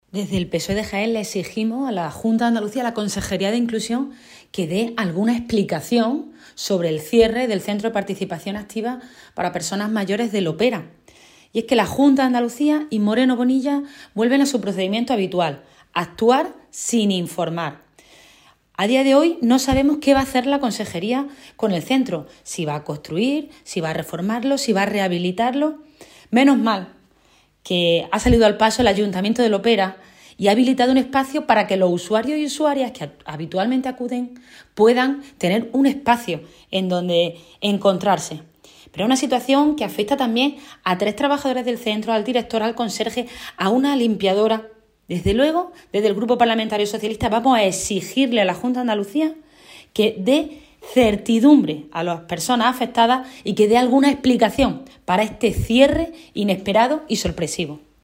Cortes de sonido
Mercedes Gámez